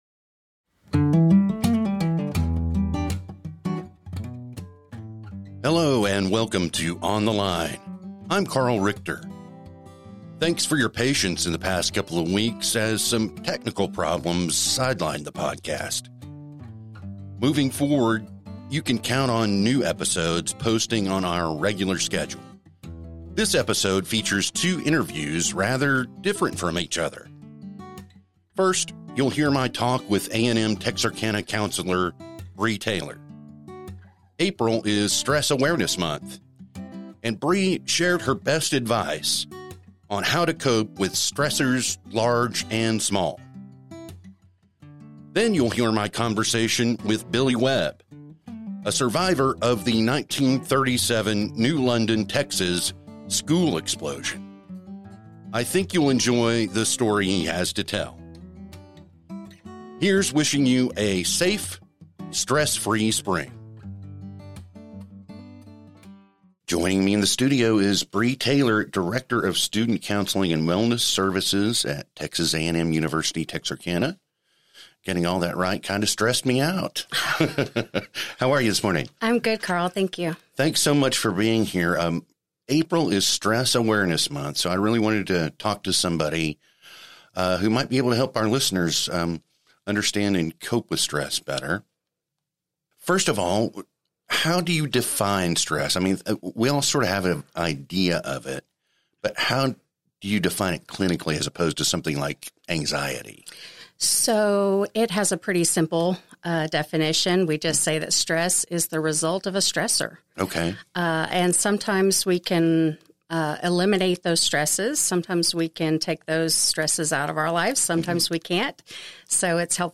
This episode features two interviews rather different from each other.